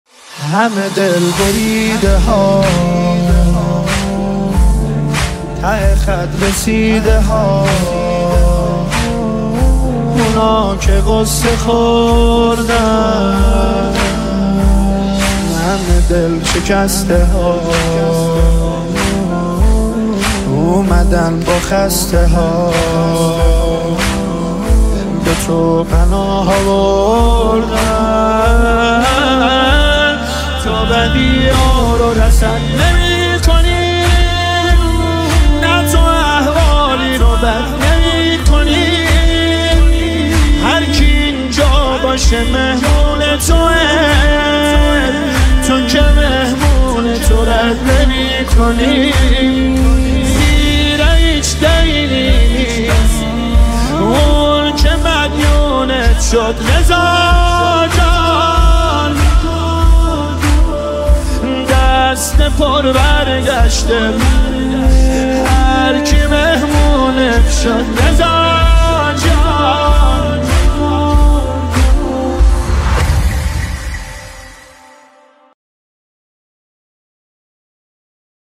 دلنشین
مداحی